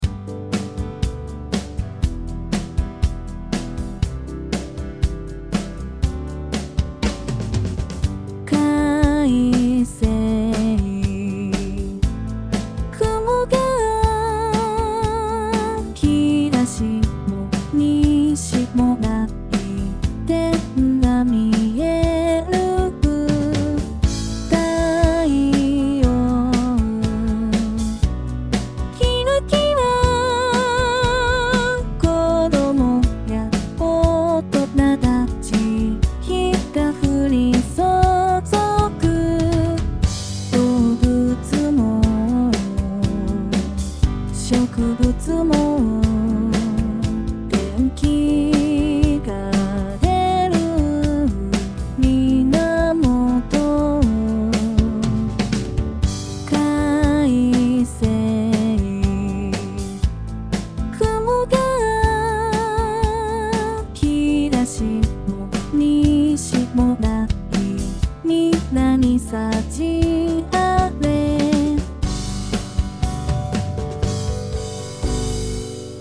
[歌]
※Band-in-a-Boxによる自動作曲